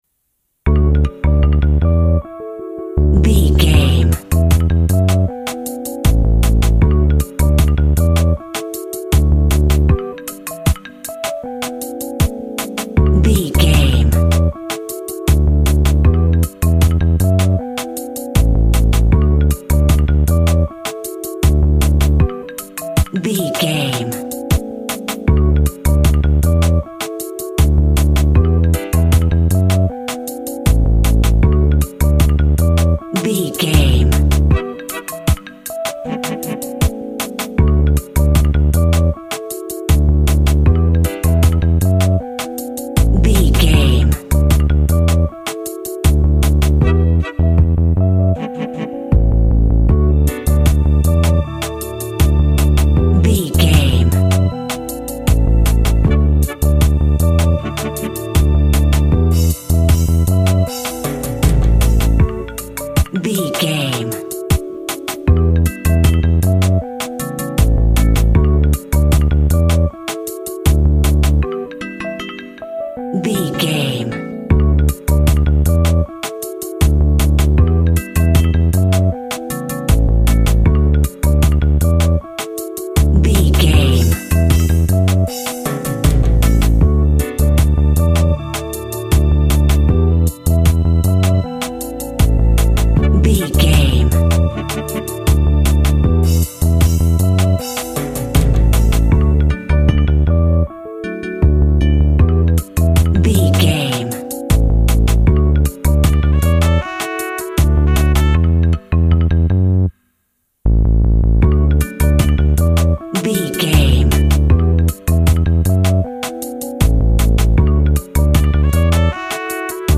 Also with small elements of Dub and Rasta music.
Aeolian/Minor
Slow
tropical
drums
bass
guitar
piano
brass
pan pipes
steel drum